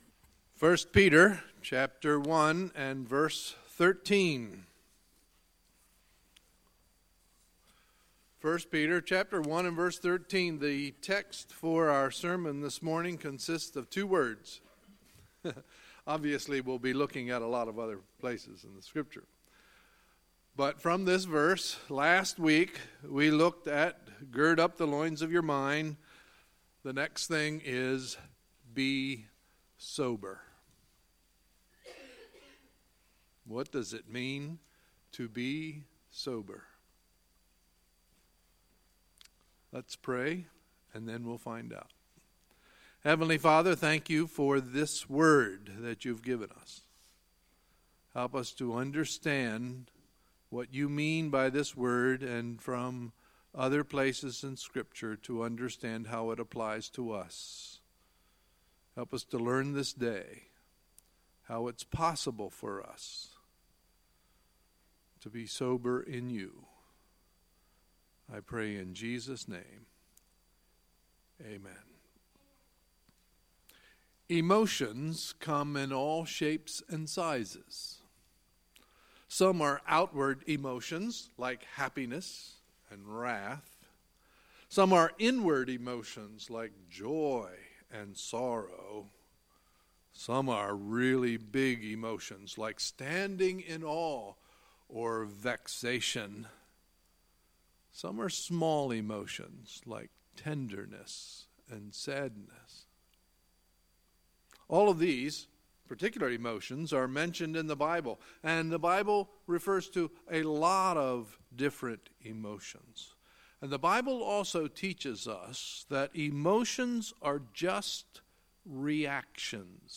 Sunday, February 18, 2018 – Sunday Morning Service